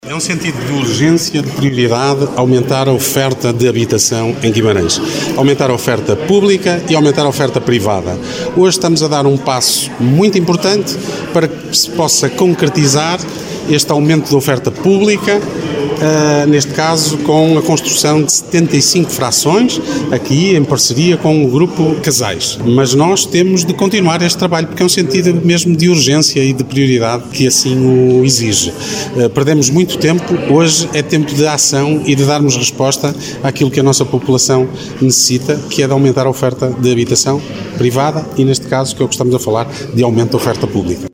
No final da cerimónia, em declarações aos jornalistas, o presidente da Câmara de Guimarães, Ricardo Araújo, destacou que este é “um passo importante para se concretizar o objetivo de aumentar a oferta pública e privada de habitação no concelho de Guimarães”